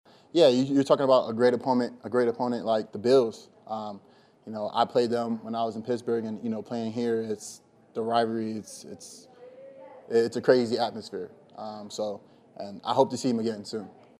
4. Chiefs WR JuJu Smith-Schuster says he liked the atmosphere